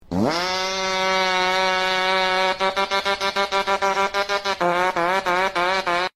Fart Troll Sound Button - Free Download & Play